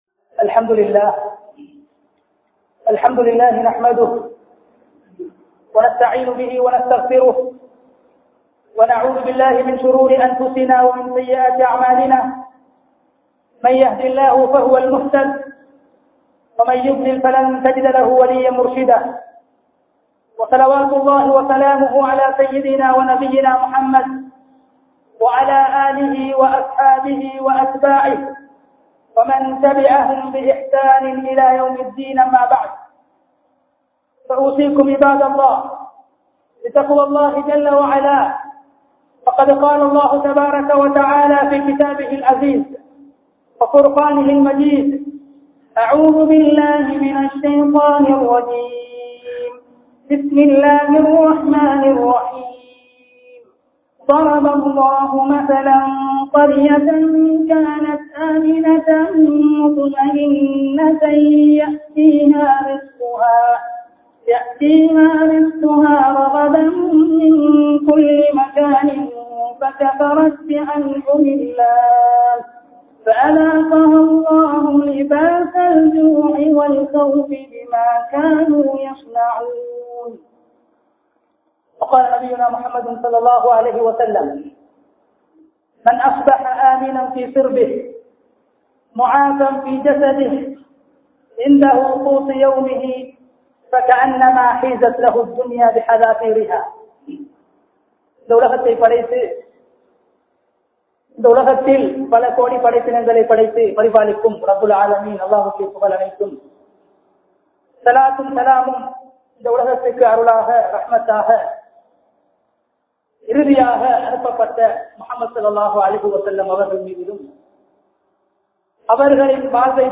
Paathuhaappai Palap Paduththum 5 (பாதுகாப்பை பலப்படுத்தும் 5) | Audio Bayans | All Ceylon Muslim Youth Community | Addalaichenai